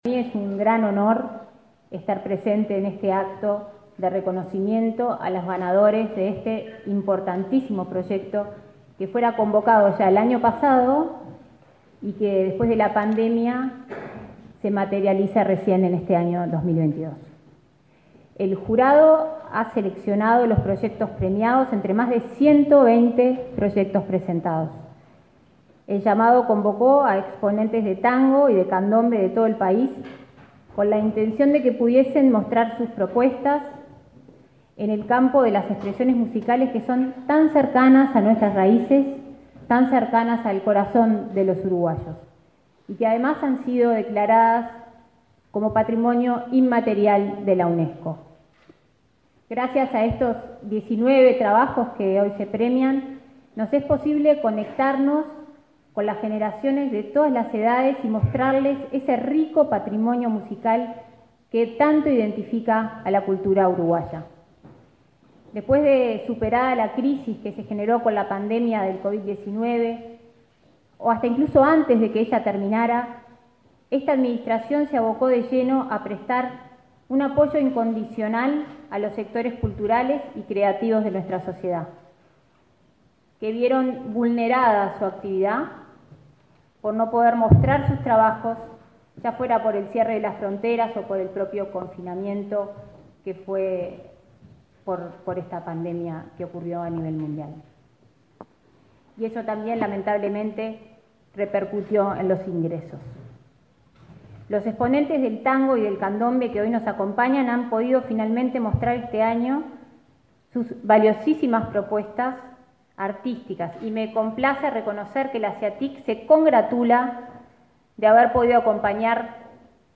Palabras de autoridades en reconocimiento a proyectos de tango y candombe
Palabras de autoridades en reconocimiento a proyectos de tango y candombe 06/09/2022 Compartir Facebook X Copiar enlace WhatsApp LinkedIn La vicecanciller, Carolina Ache, y la subsecretaria de Educación y Cultura, Ana Ribeiro, participaron en el reconocimiento a 20 proyectos de tango y candombe, en el Palacio Taranco de Montevideo.